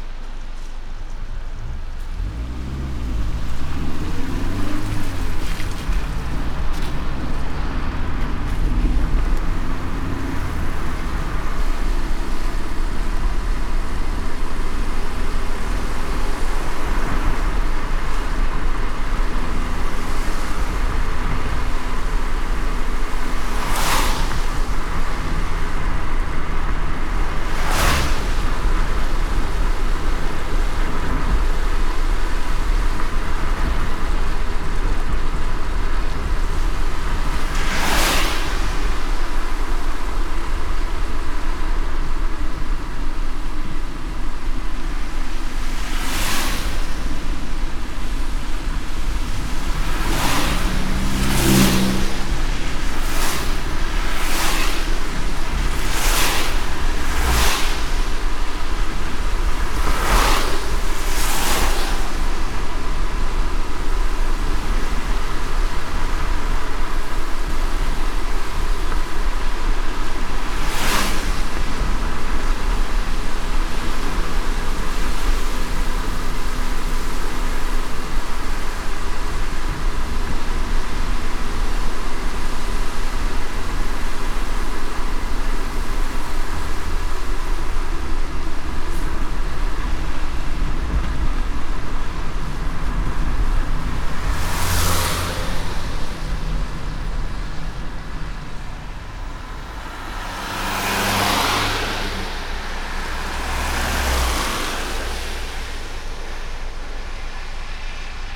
driving-in-the-rain.wav